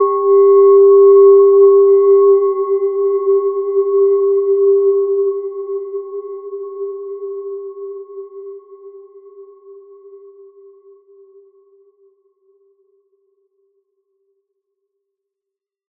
Gentle-Metallic-4-G4-mf.wav